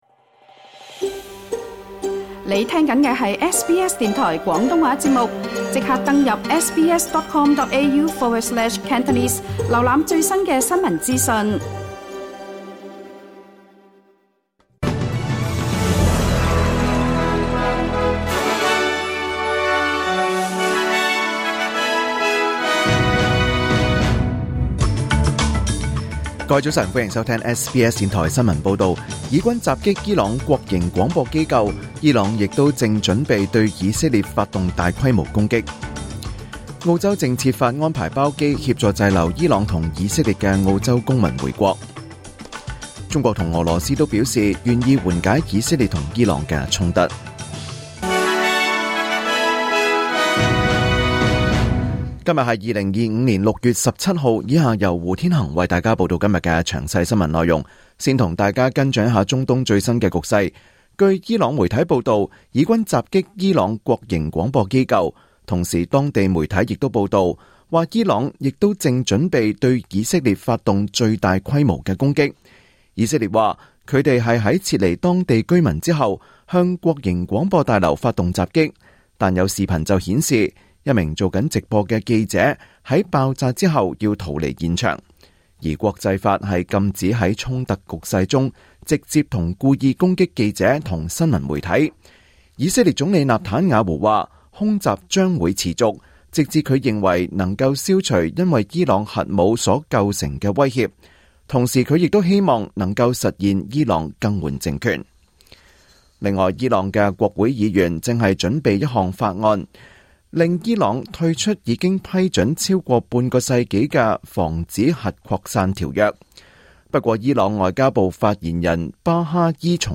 2025年6月17日SBS廣東話節目九點半新聞報道。